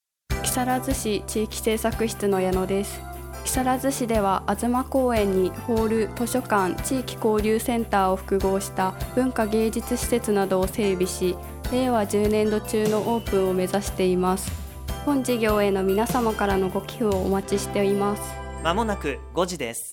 木更津の魅力をPRするCMを放送しています！
出演者：木更津市地域政策室